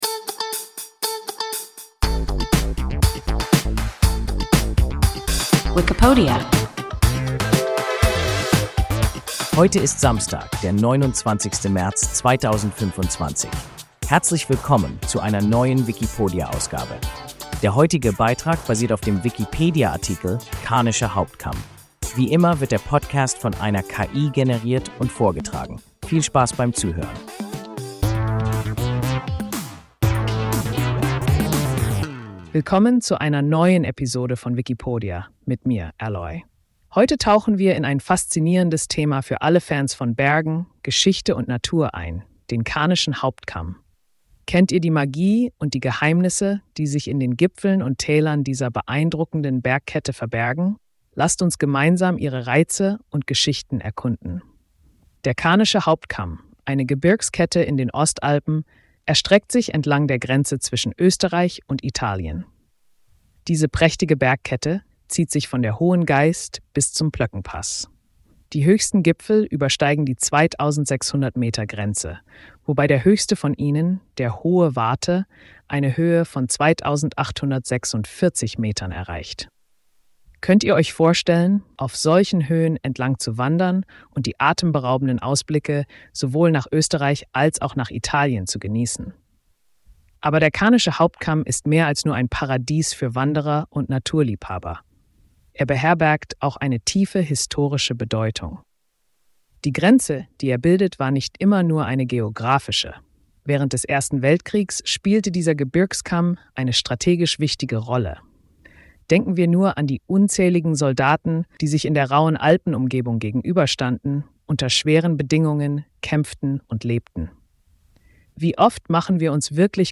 Karnischer Hauptkamm – WIKIPODIA – ein KI Podcast